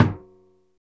PRC SURDO 02.wav